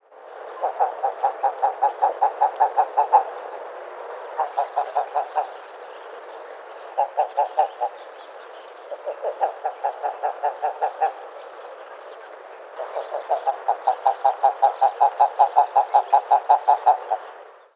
Eiderente
Hören Sie sich hier die Stimme der Eiderente an: Laute der Eiderente
393-eiderente_laute-soundarchiv.com_2.mp3